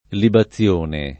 libazione [ liba ZZL1 ne ]